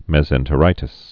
(mĕz-ĕntə-rītĭs, mĕs-)